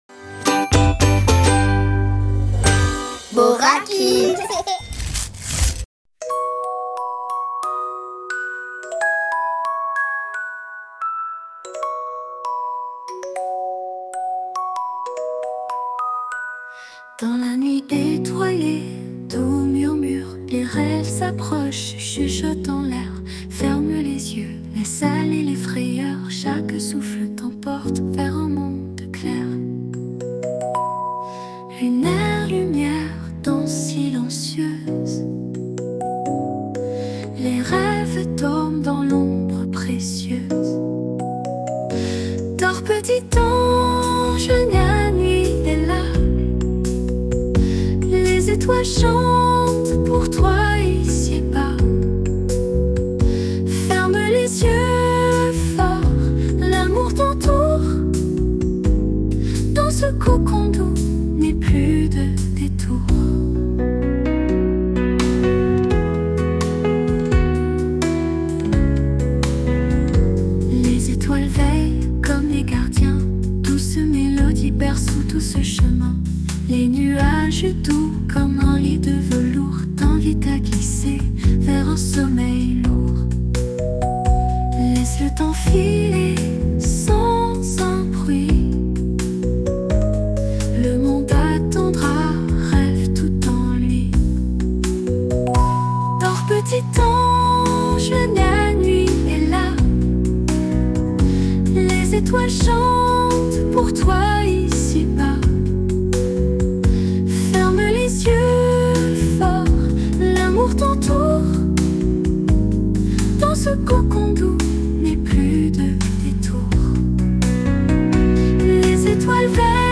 berceuse pour enfants